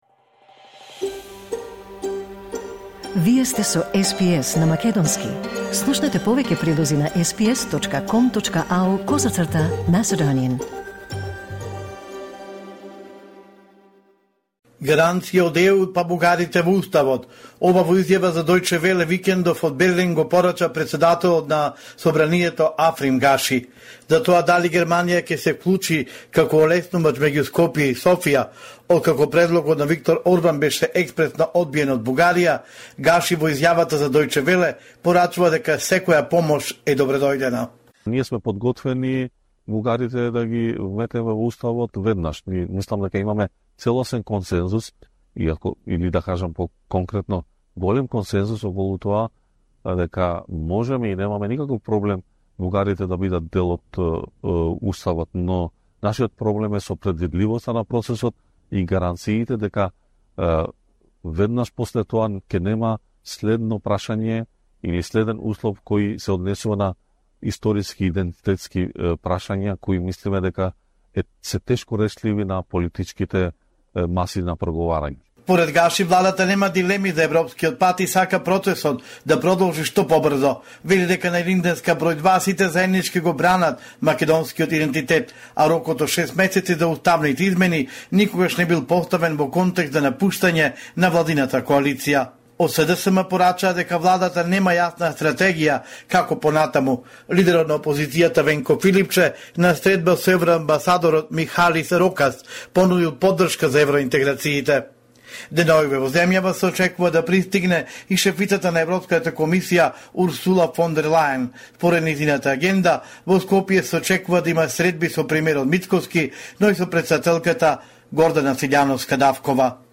Homeland Report in Macedonian 21 October 2024